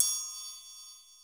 TRIANGLE OP.wav